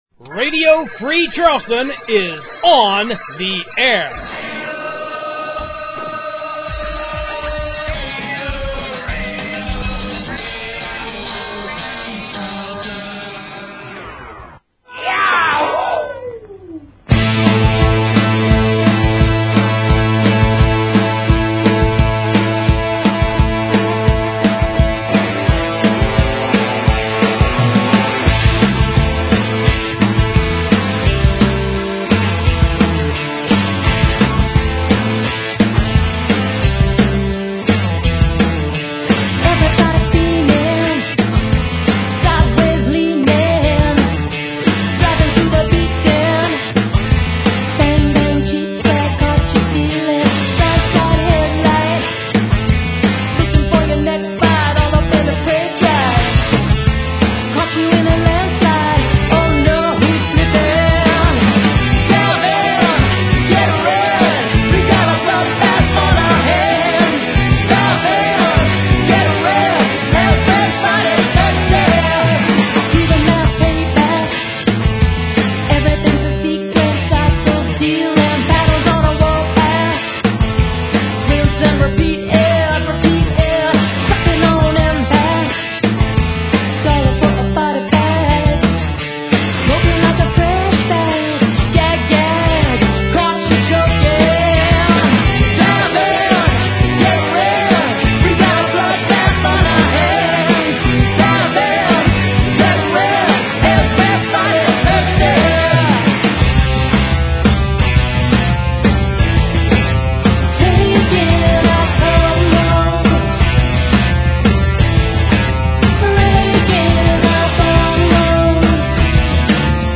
Radio Free Charleston brings you a new show with one brand-new hour, and a special encore of two episodes of Radio Free Charleston Volume Four, from a decade ago. This show is mostly local, West Virginia artists, with a sprinkling of independent musicians just to keep you on your toes.